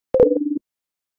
LowBattery.ogg